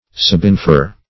Search Result for " subinfer" : The Collaborative International Dictionary of English v.0.48: Subinfer \Sub`in*fer"\, v. t. & i. To infer from an inference already made.